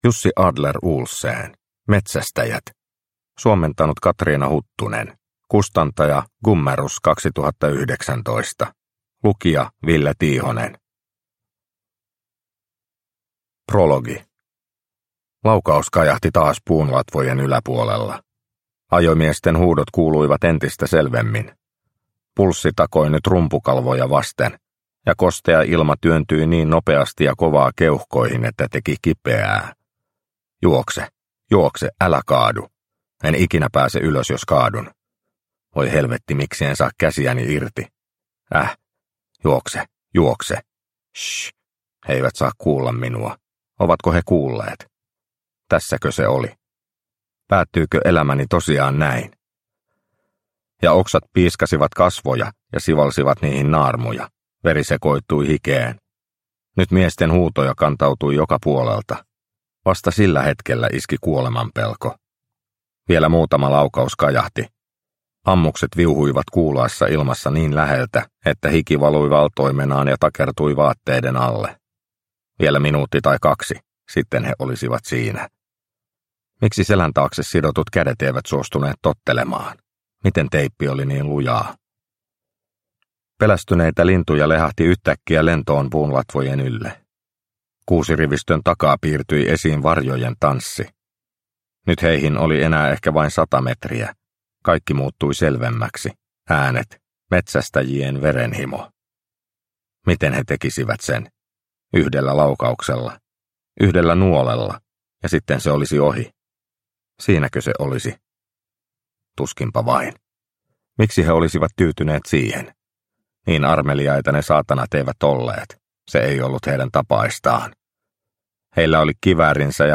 Metsästäjät – Ljudbok – Laddas ner